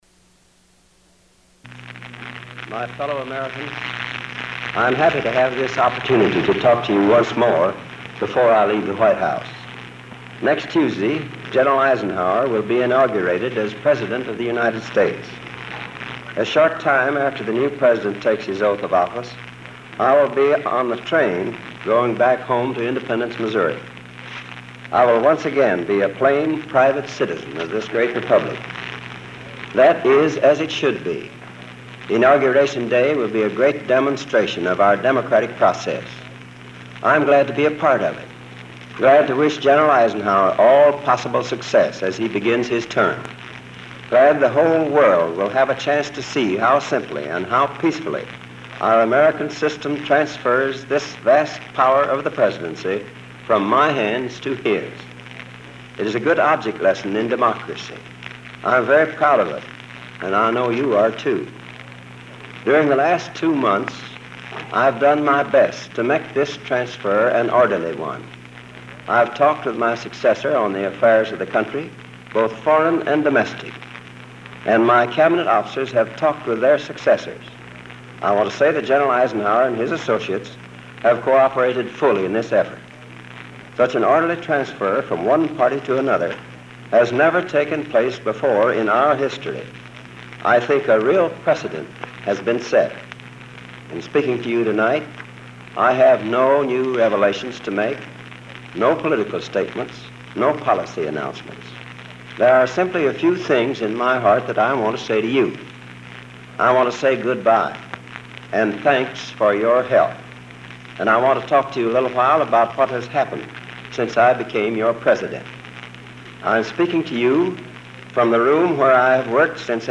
January 15, 1953: Farewell Address | Miller Center
Presidential Speeches | Harry S. Truman Presidency